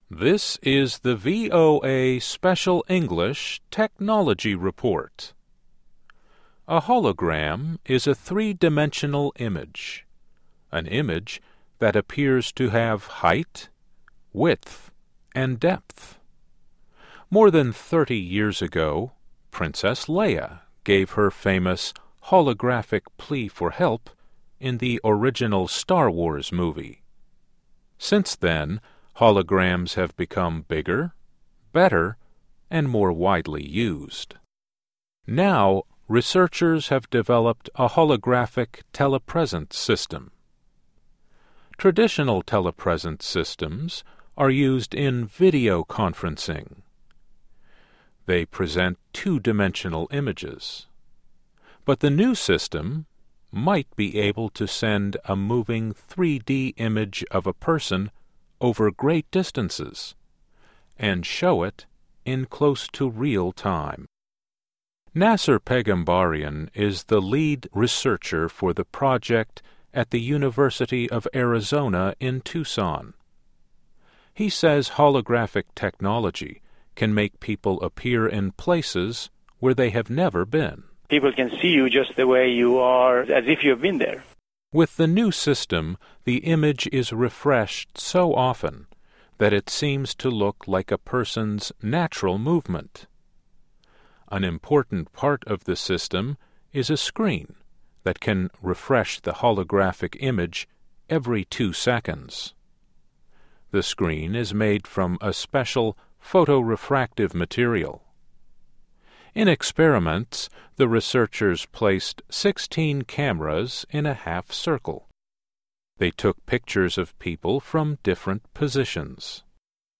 Technology Report